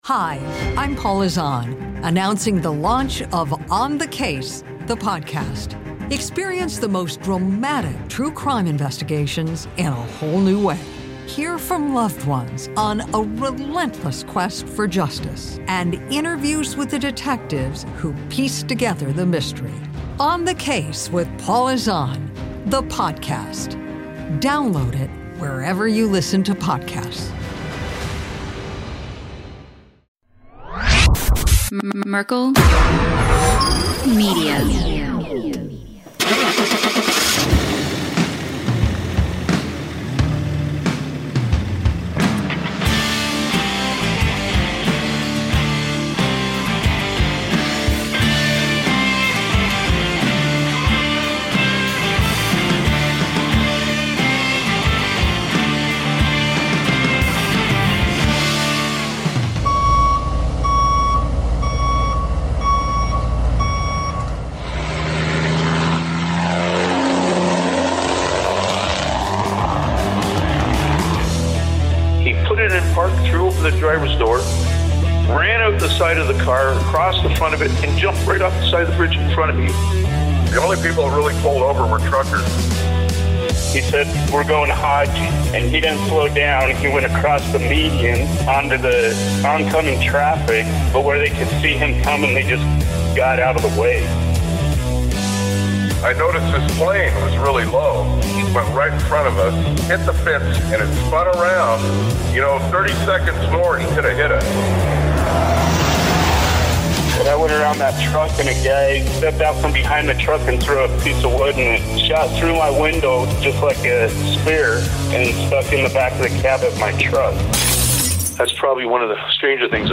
There is no shortage of entertaining conversation, so sit back and enjoy the ride!